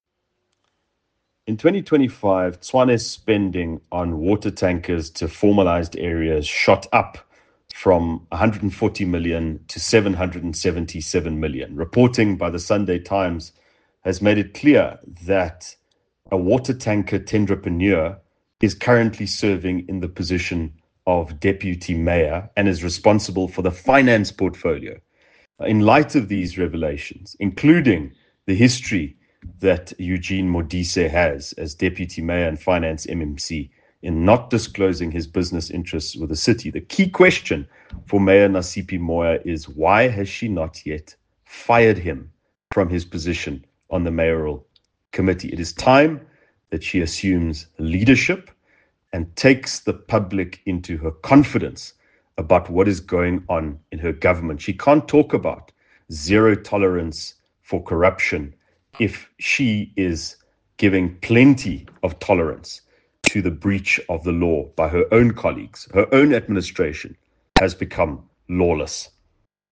Afrikaans soundbites by Ald Cilliers Brink